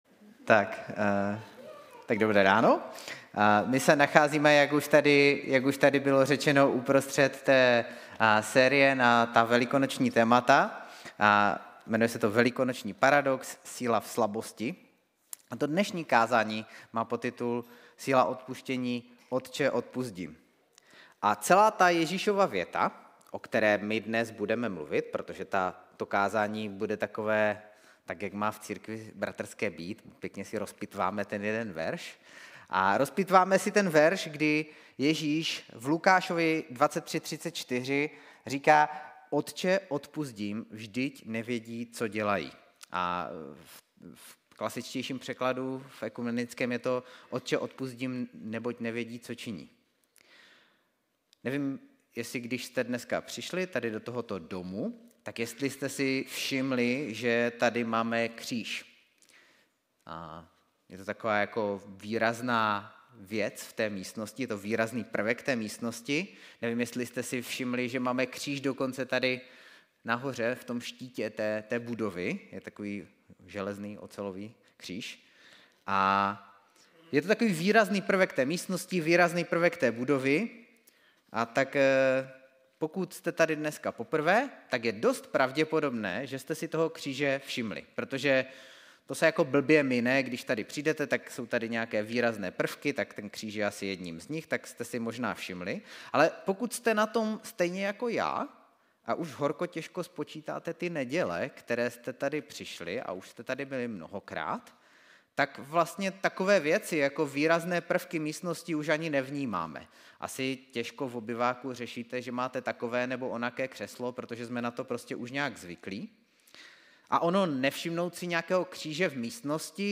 Kázání
Záznamy kázání z nedělních bohoslužeb a další vyučování z našeho křesťanského společenství.